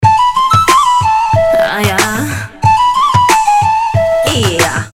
• Качество: 320, Stereo
веселые
женский голос
Флейта
Восточные мотивы с женским голосом хорошо зайдут на смс-ку